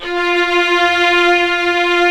55m-orc09-F3.wav